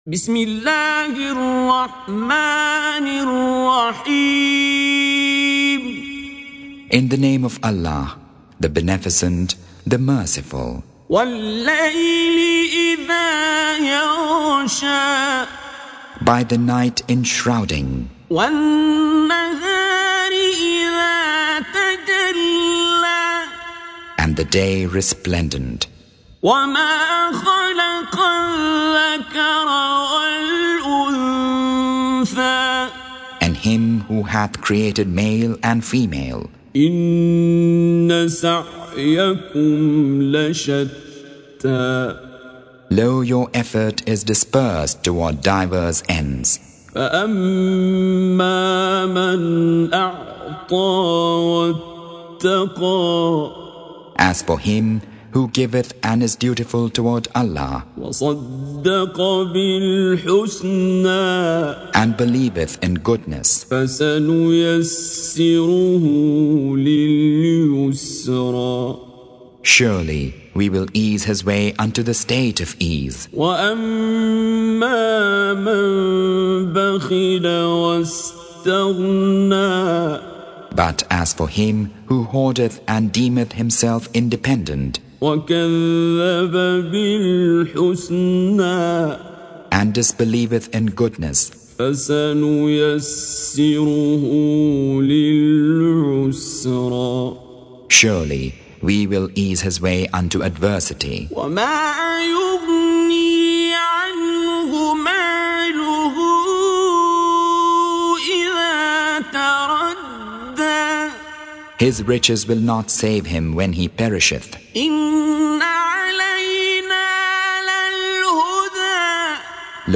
Surah Sequence تتابع السورة Download Surah حمّل السورة Reciting Mutarjamah Translation Audio for 92. Surah Al-Lail سورة الليل N.B *Surah Includes Al-Basmalah Reciters Sequents تتابع التلاوات Reciters Repeats تكرار التلاوات